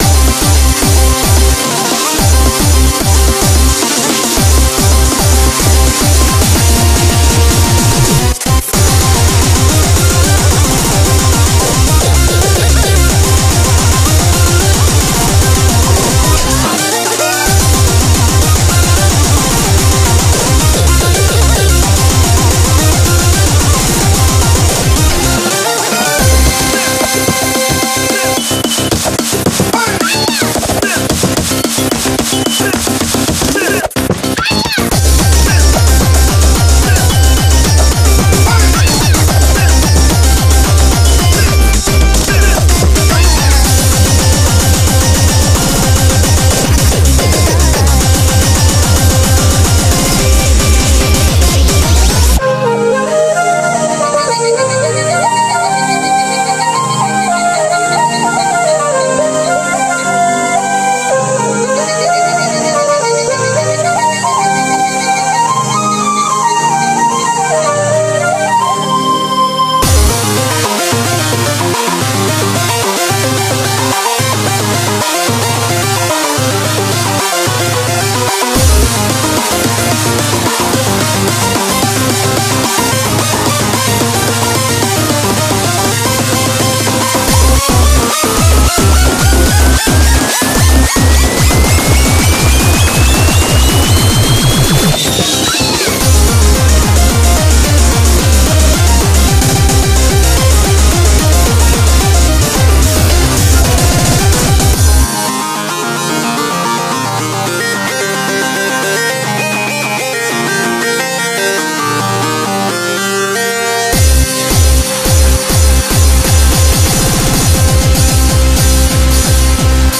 BPM48-440
Audio QualityPerfect (Low Quality)